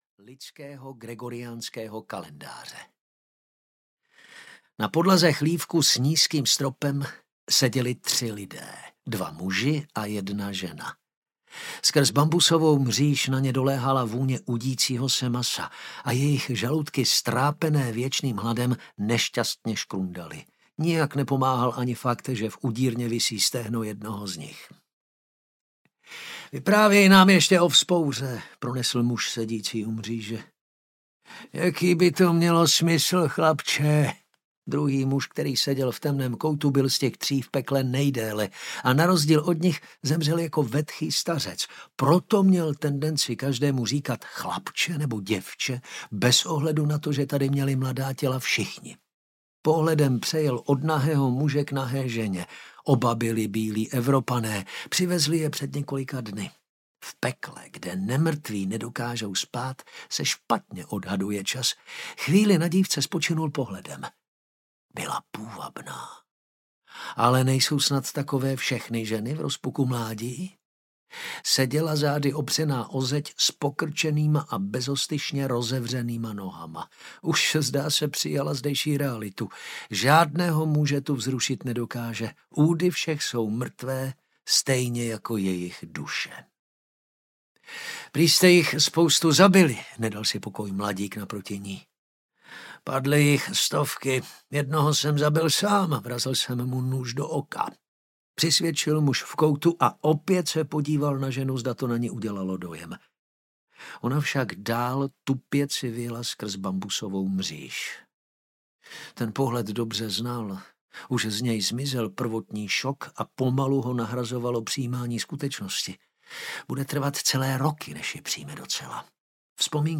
Ukázka z knihy
inferium-ii-invaze-audiokniha